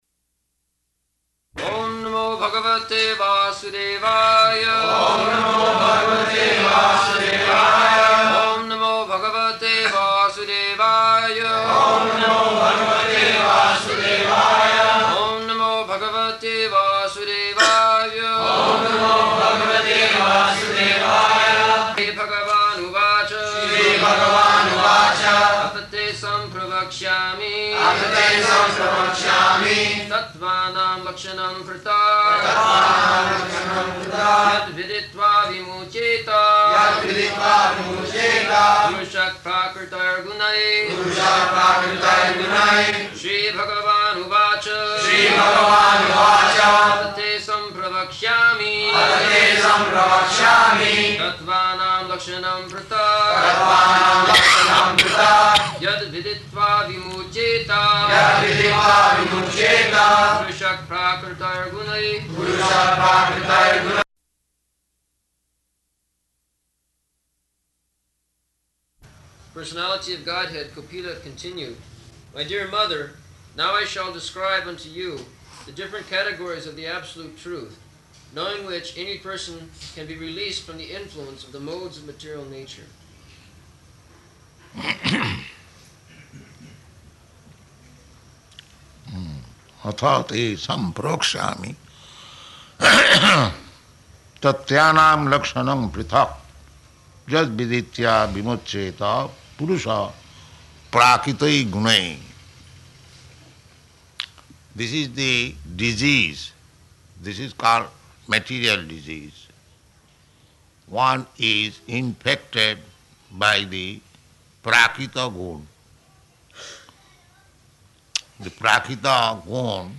December 13th 1974 Location: Bombay Audio file
[devotees repeat]